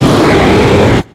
Cri de Latios dans Pokémon X et Y.